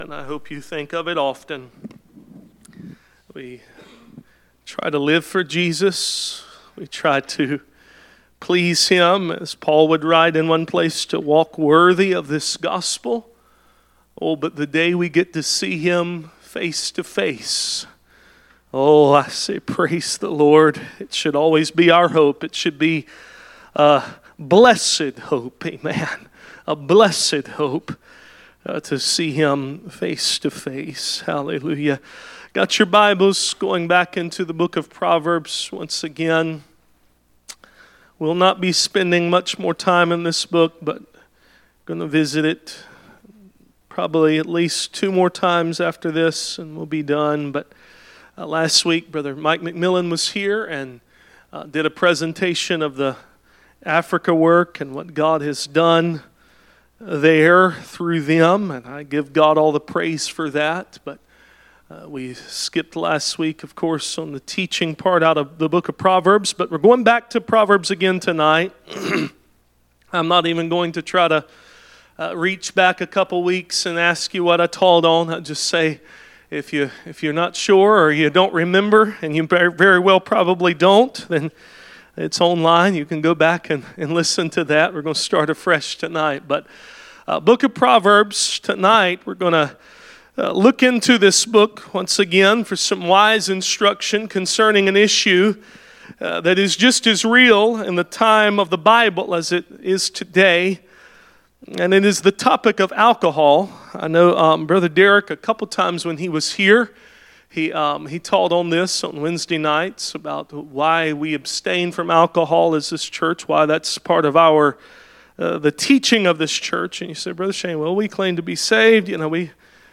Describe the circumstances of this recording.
Proverbs 23 Service Type: Midweek Meeting « Make room for the mantle Church Attendance